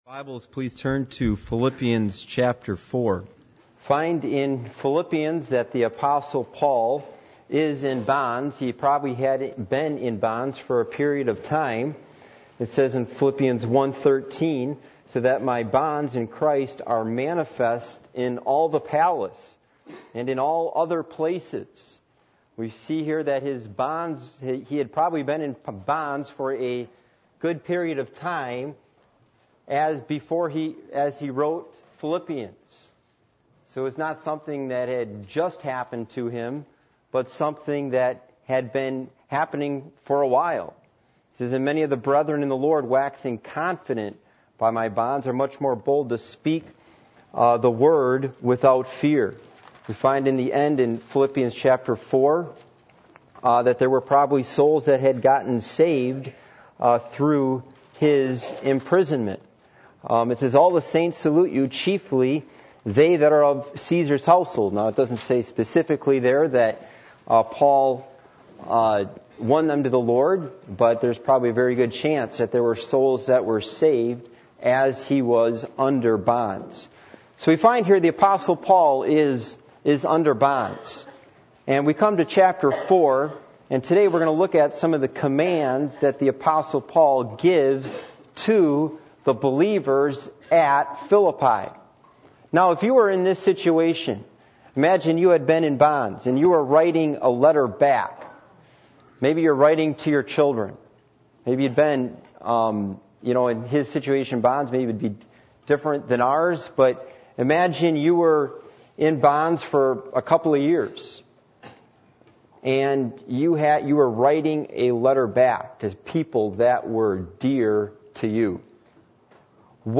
Passage: Philippians 4:1-9 Service Type: Sunday Morning